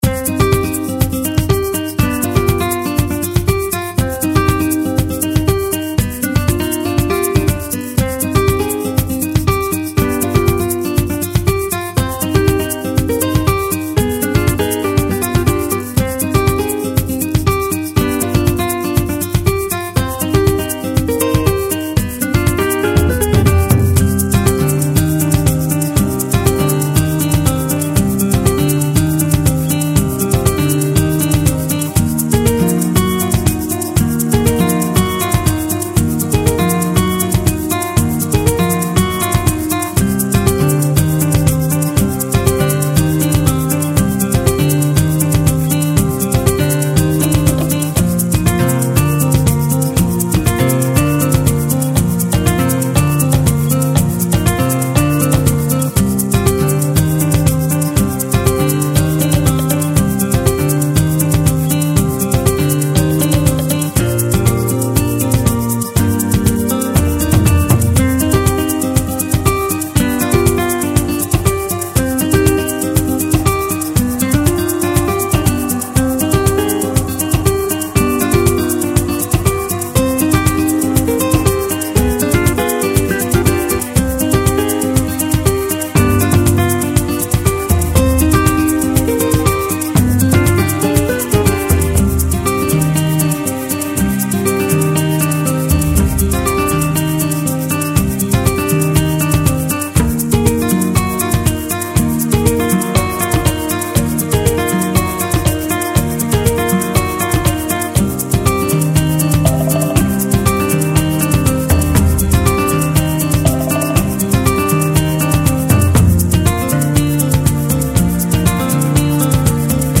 Largo [40-50] plaisir - guitare acoustique - - -